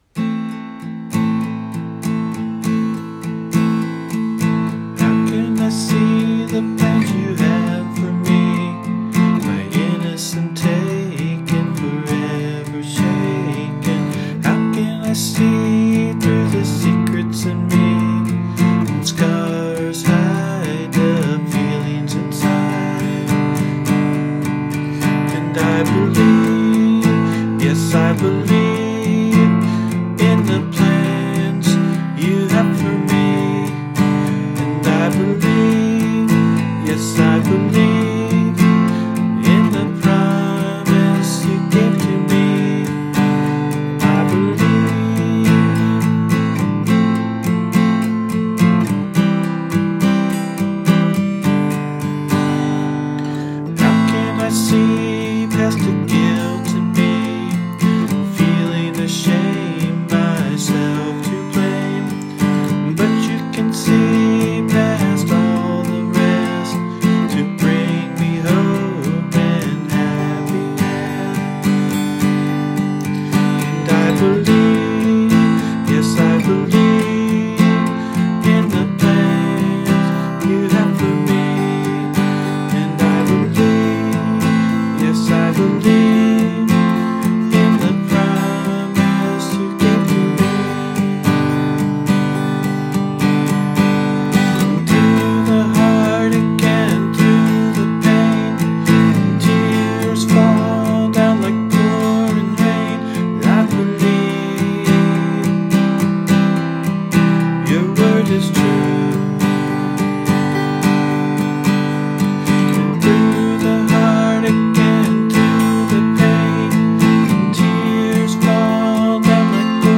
Here’s the client demo giving me an idea of his song:
i-believe-client-demo.mp3